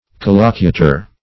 Search Result for " collocutor" : The Collaborative International Dictionary of English v.0.48: Collocutor \Col"lo*cu`tor\, n. [L. collocutor] One of the speakers in a dialogue.